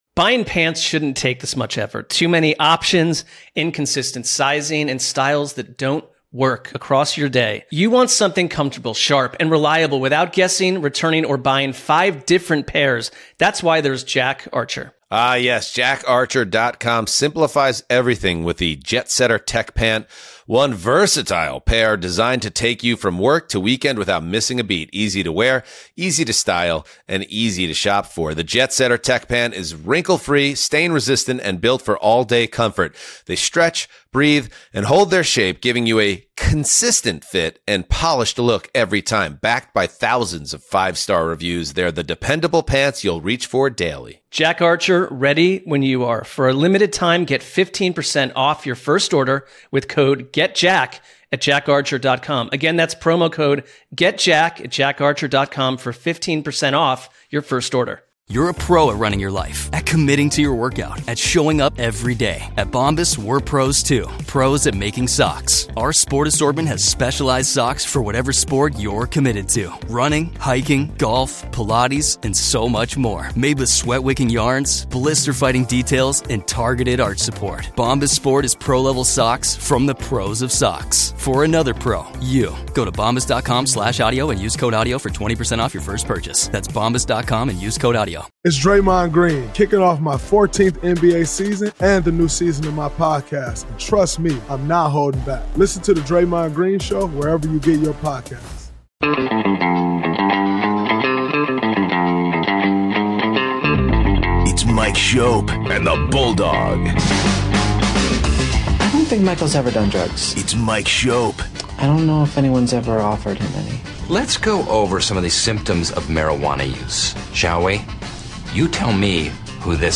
Best Bills Interviews on WGR: Feb. 16-20